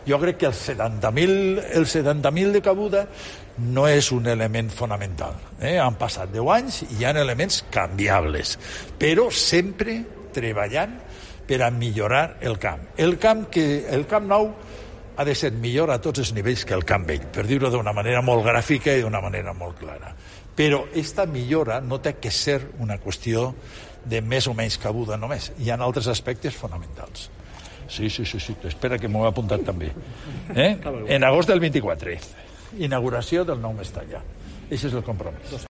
AUDIO. Joan Ribó confirma la fecha prevista para la inauguración del estadio